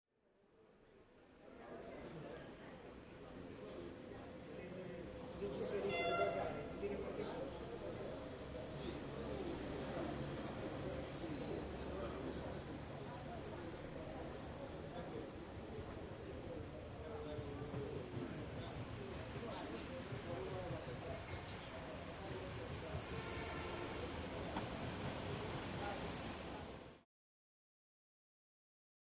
Train Station | Sneak On The Lot